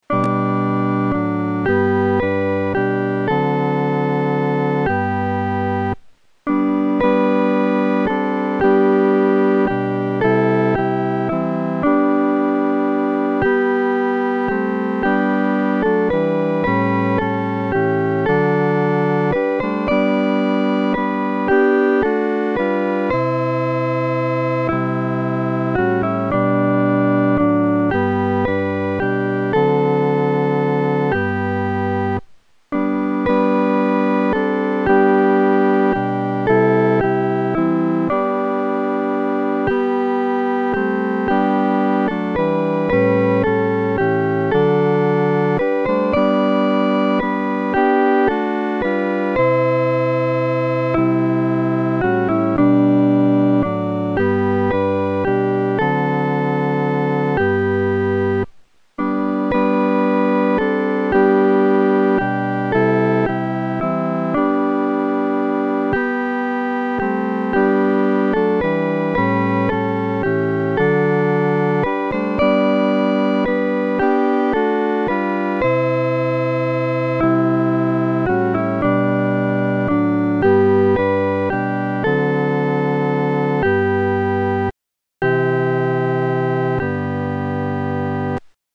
伴奏
四声
指挥在带领诗班时，表情和速度应采用温柔而缓慢地。